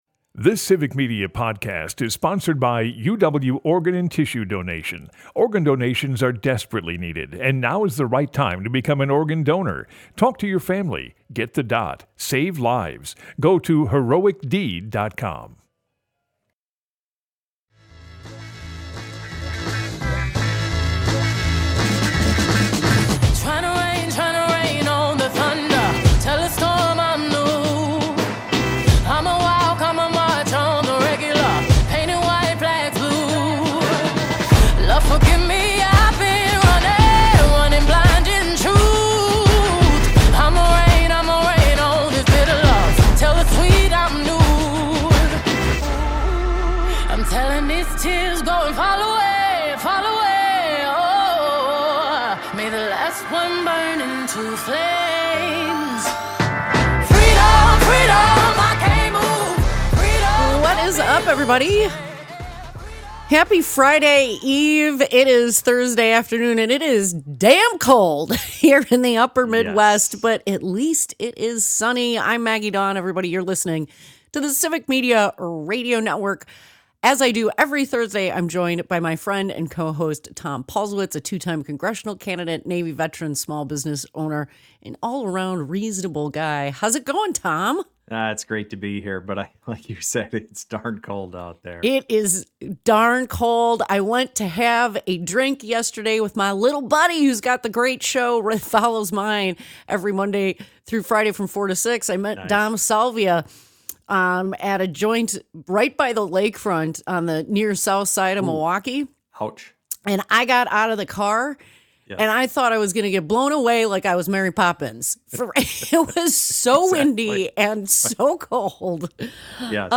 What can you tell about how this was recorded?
Broadcasts live, 2 - 4 p.m. across Wisconsin.